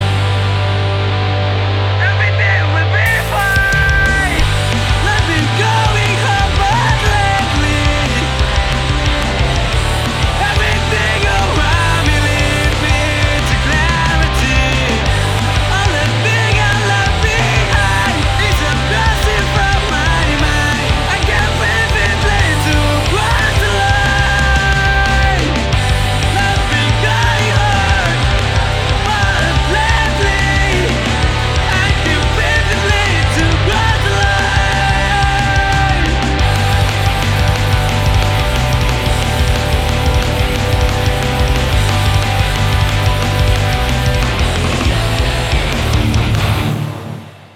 kuintet pop-punk